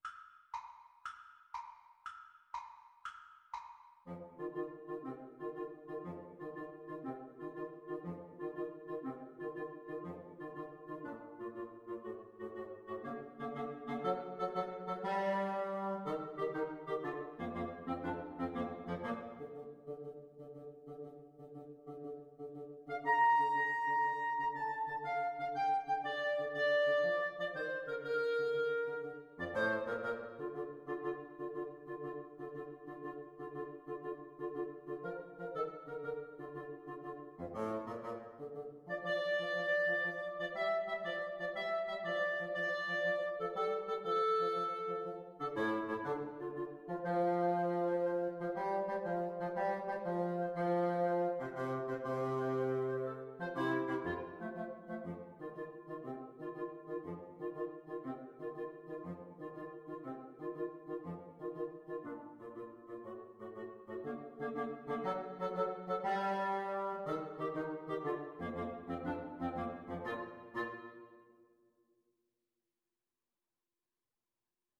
Woodwind Trio version
FluteClarinetBassoon
6/8 (View more 6/8 Music)